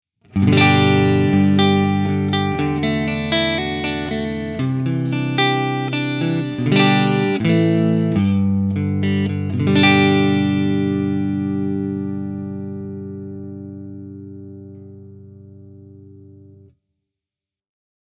Tutte le clip audio sono state registrate con amplificatore Fender Deluxe e una cassa 2×12 con altoparlanti Celestion Creamback 75.
Clip 1 – Stratocaster Clean, Over Drive in Original Buffered Bypass
Chitarra: Fender Stratocaster (pickup al manico)
Strat-Clean.mp3